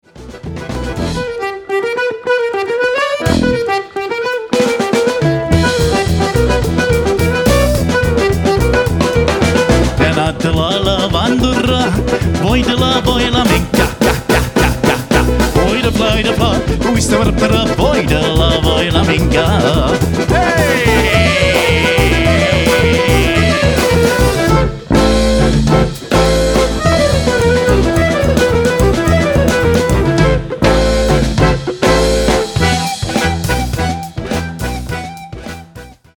the pioneers of Russian Disco with their anniversary CD